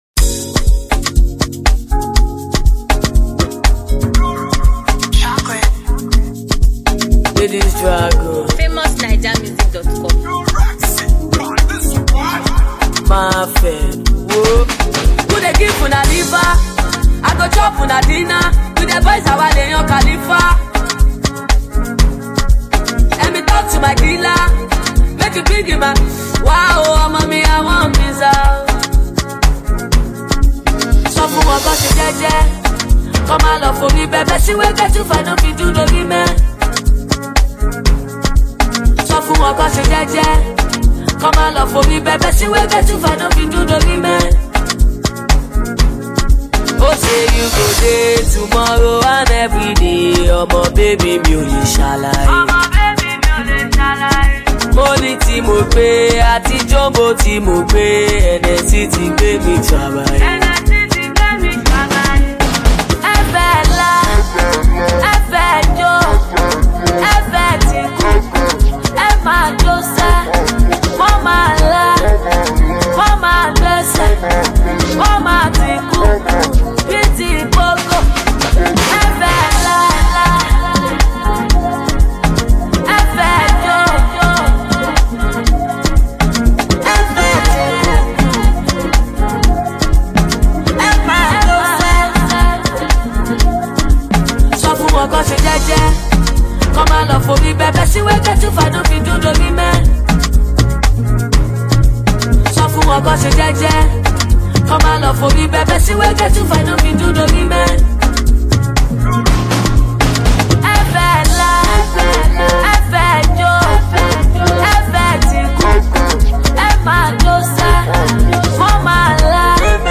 Skilled Nigerian female vocalist, rapper, and lyricist
crush tune